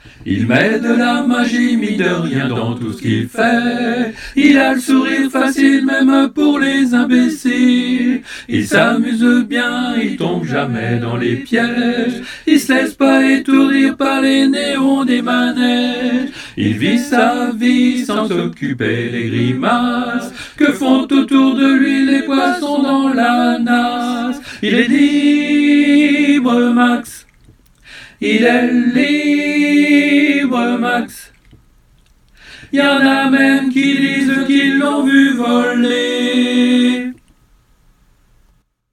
à 4 voix